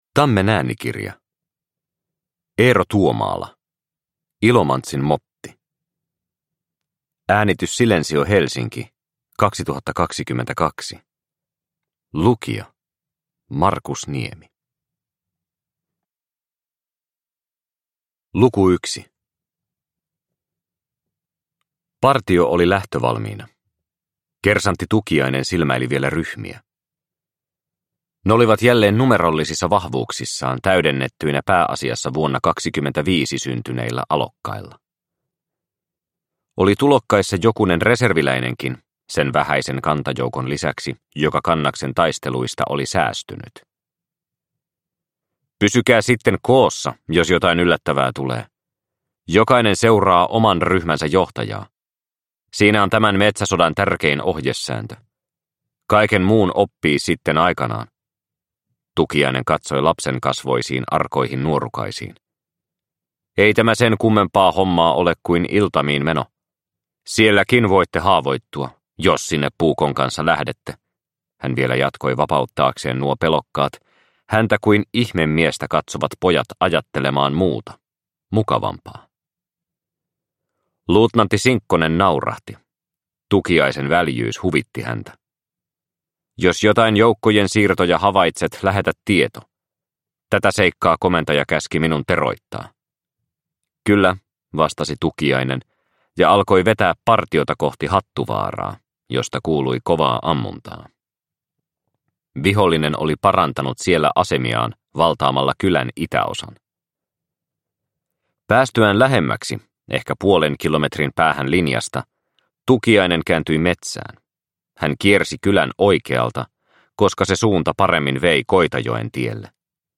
Ilomantsin motti – Ljudbok – Laddas ner